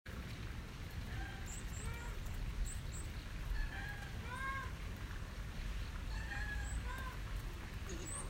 これはオスが高い声を発し、メスが少し低い声で返事をしています。